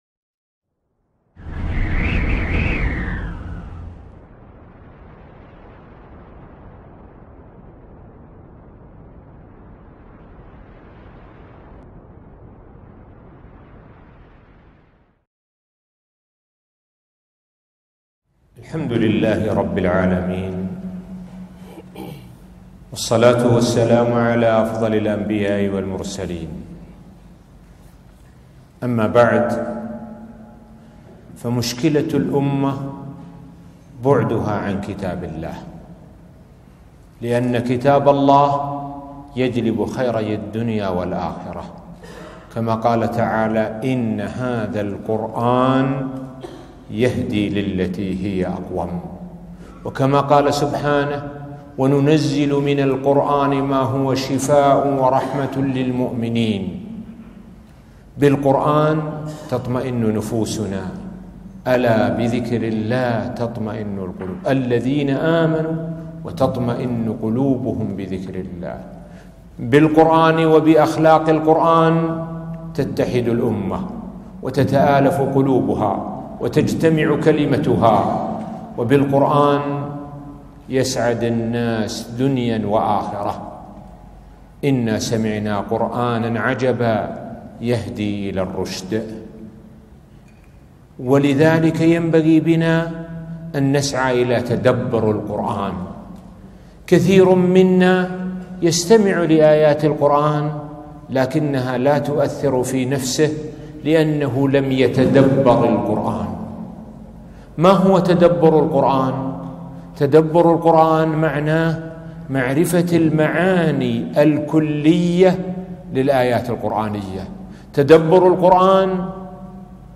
محاضرة قيمة - أثر تدبر القرآن على النفوس